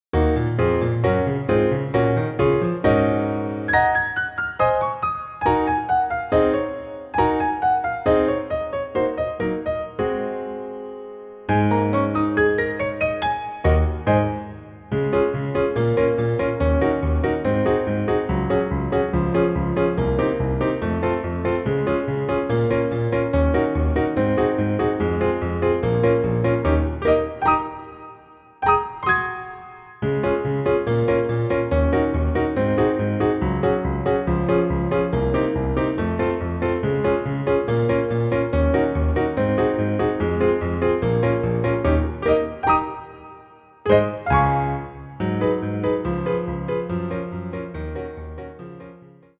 Version live